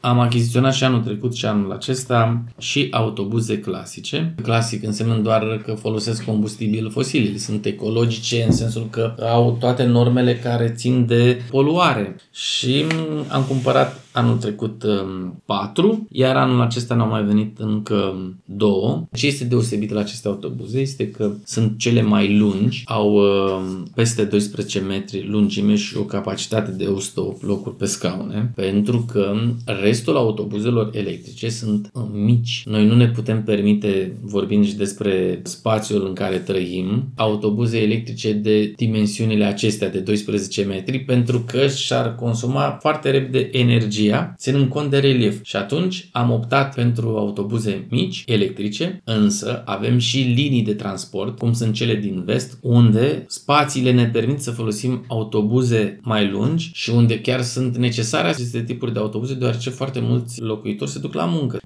Primarul municipiului Tulcea, Ștefan Ilie, a explicat pentru Radio Constanța de ce primăria a ales să cumpere și autobuze pe combustibil fosil.